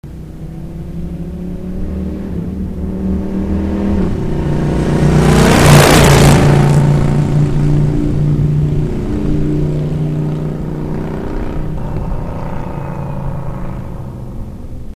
U výfuků 2 si můžete 3x poslechnou zvuk
driveby.mp3